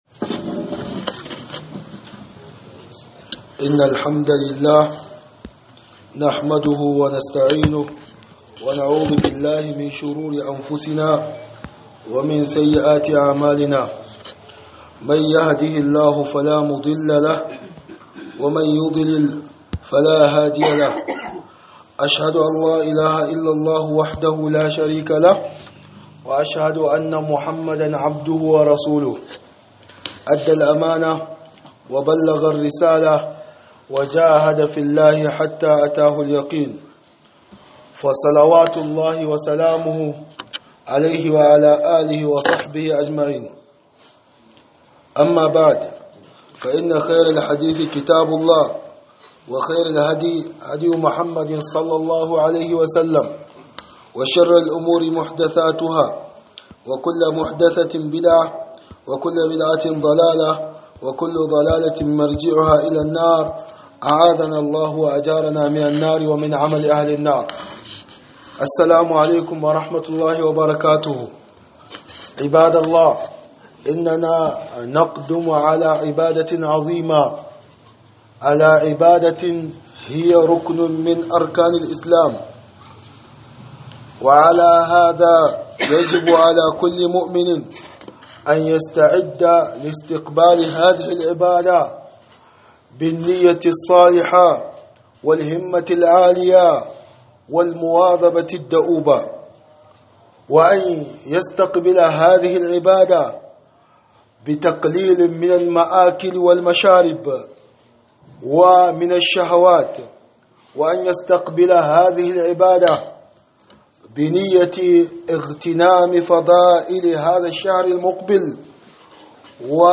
خطبة بعنوان من فضائل صوم رمضان وأحكامه